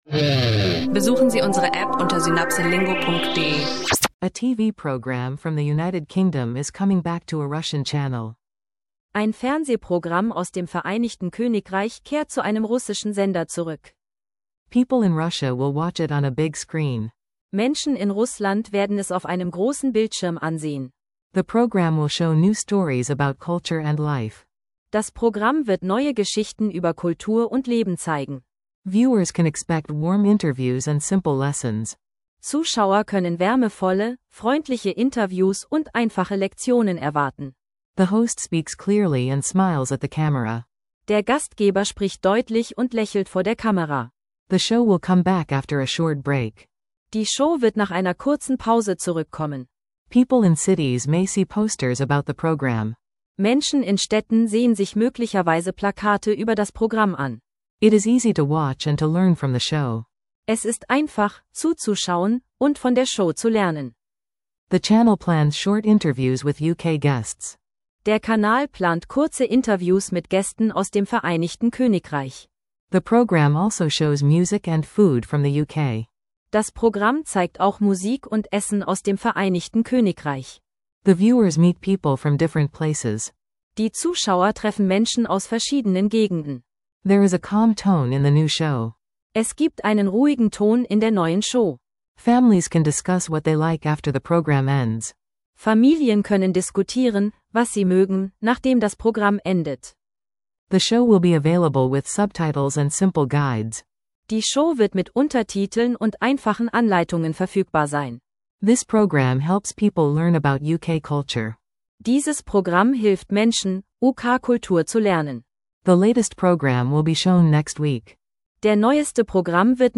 klare Aussprache zum Englisch lernen leicht gemacht.